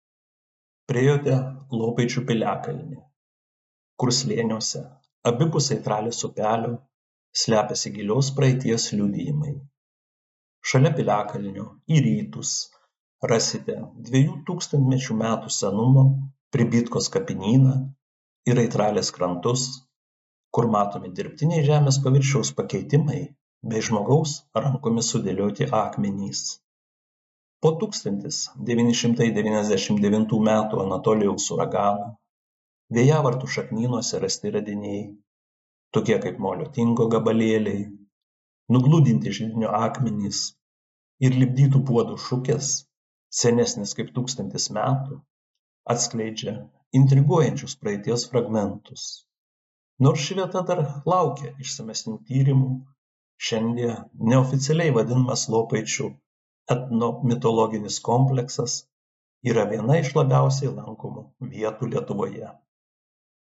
Jūs klausote miškininko pasakojimo